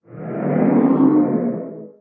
guardian_idle1.ogg